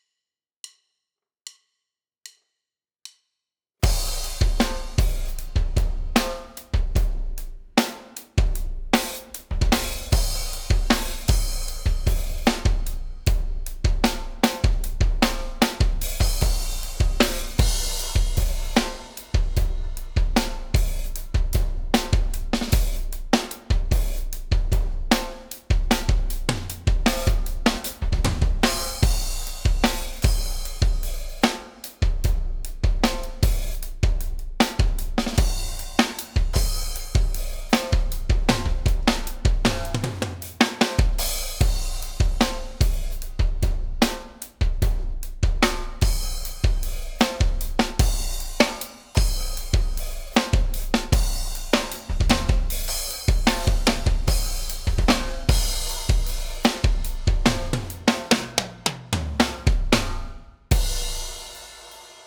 B = Digital Summing – via Sonar
Sounds like there’s a bit of clipping going on, and it’s emphasized in A. B just sounds smoother, but it also sounds narrower, less stereo depth.
B in the other hand has more harmonics but lacks the cohesion of track A
I prefer B … seems to have more life in it 🙂 at least on laptop speakers